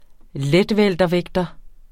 Udtale [ ˈlεdˌvεlˀdʌˌvεgdʌ ]